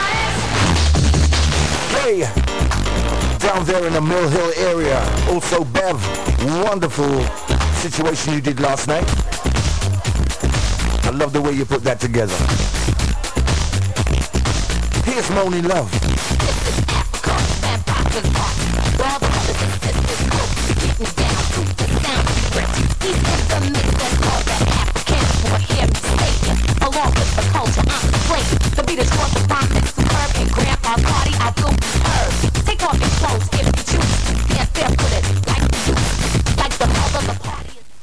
Here are my pirate loggings of FM-stations (All heard in Finland via Sporadic-E) !!